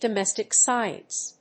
音節domèstic scíence